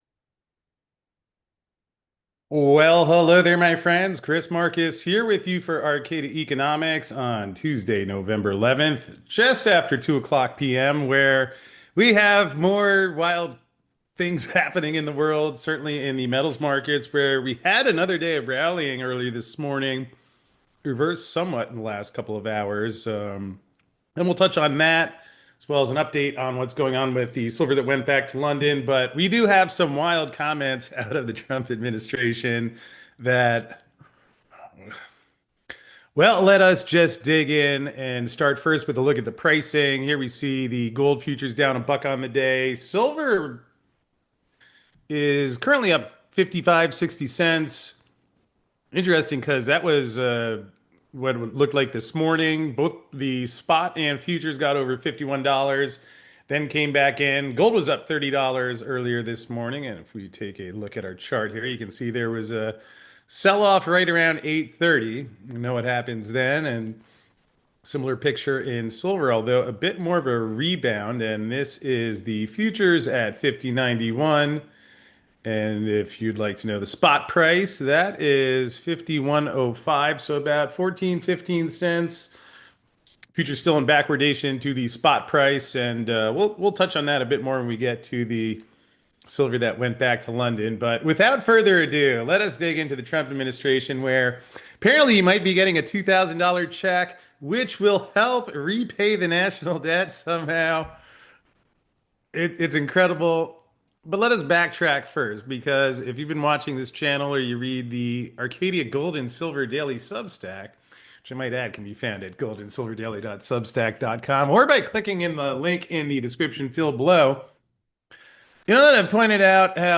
But lately, things are getting a little bit out of control with some of the statements coming out of the Trump administration, which we dig into in today's live show.